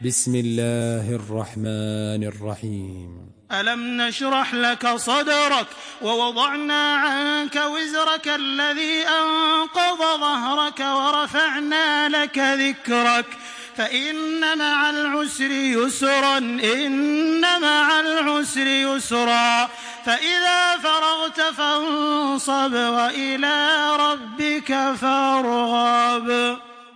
تراويح الحرم المكي 1426
مرتل حفص عن عاصم